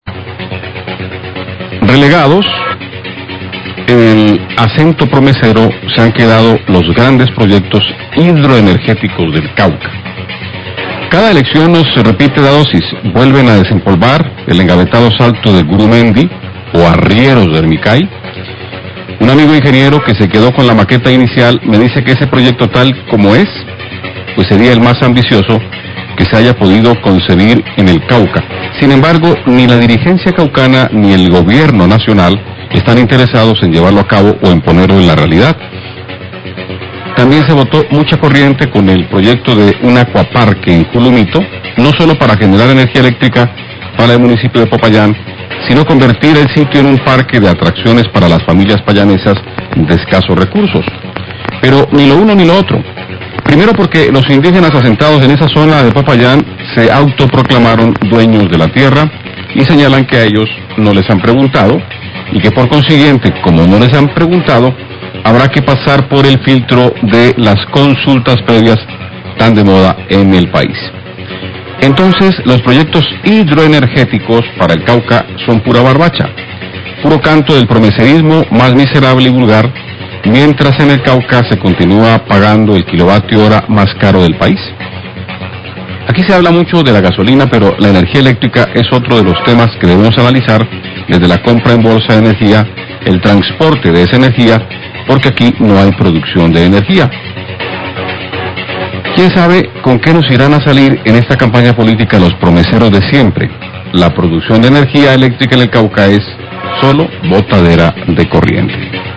Radio
editorial